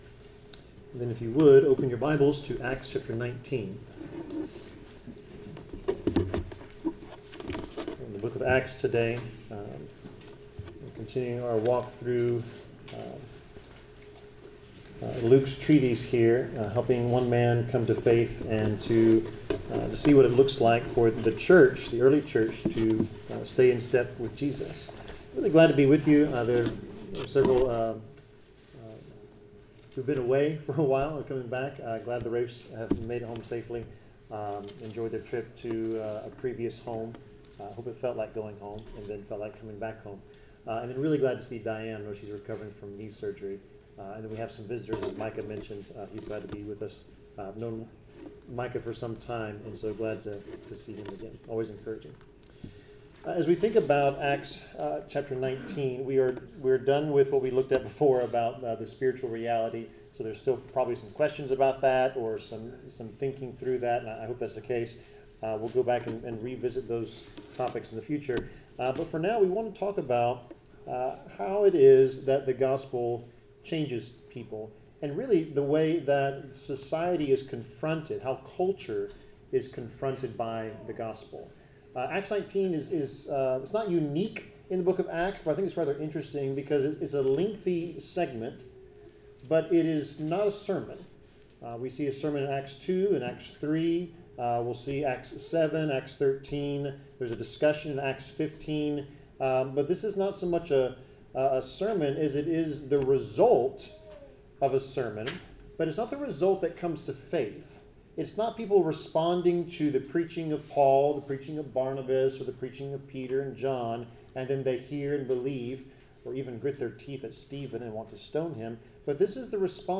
Acts 19 Service Type: Sermon « Bible class